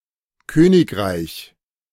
3. ^ German: [ˈkøːnɪkʁaɪç]